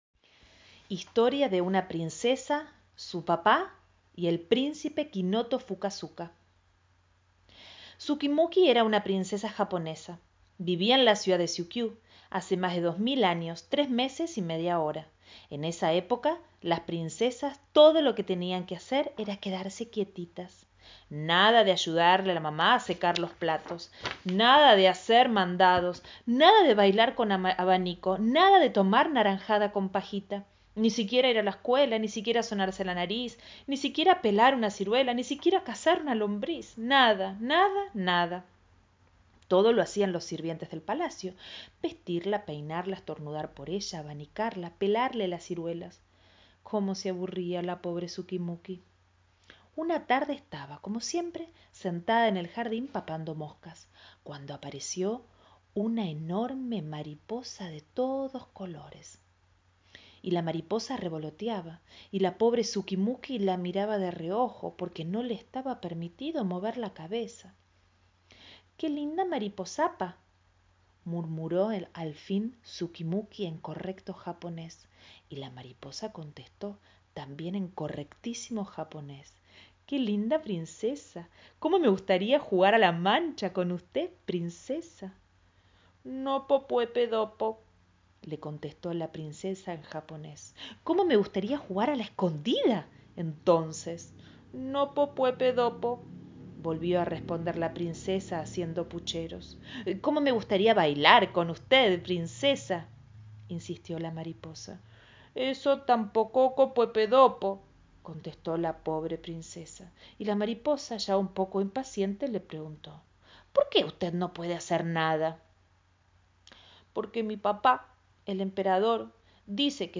lectura